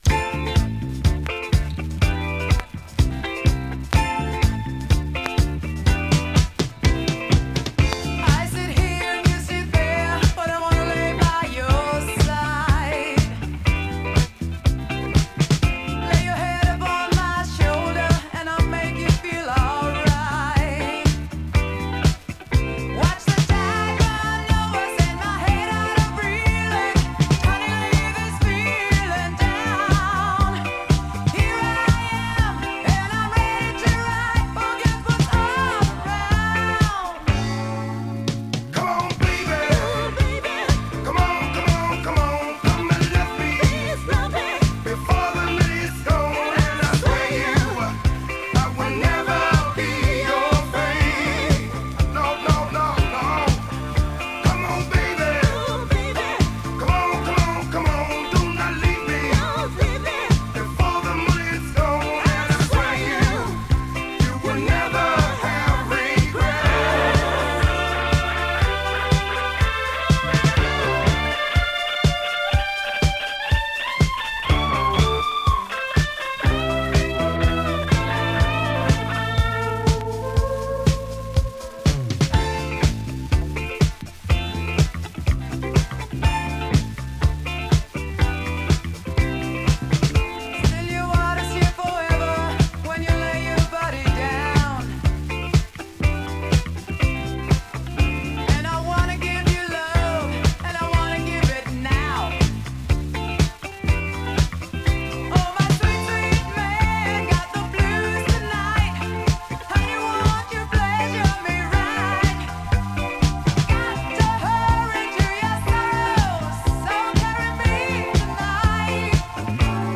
Cosmic Classic!ドイツ産ディスコ・グループ。
【DISCO】